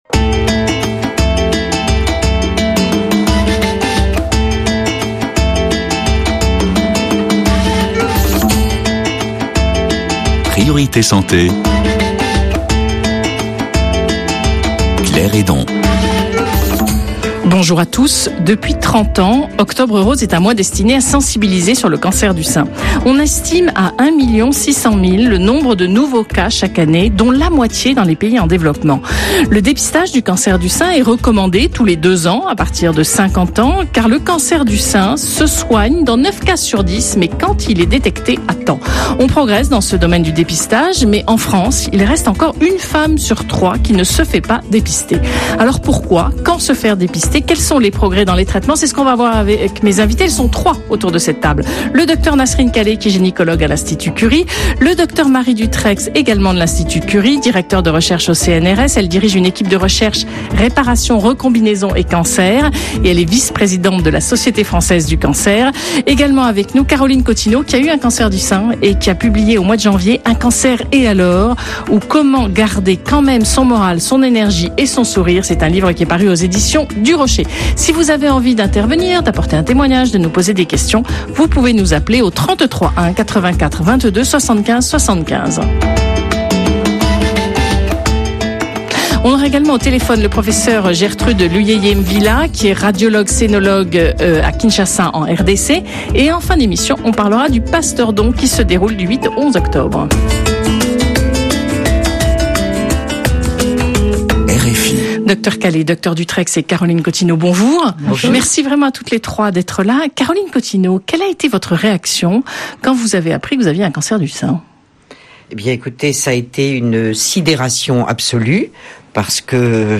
Partagez avec vos apprenant(s) le témoignage d'une femme atteinte d'un cancer du sein et permettez à vos étudiants d'acquérir la bonne posture devant des patients exprimant leur ressenti face à la maladie.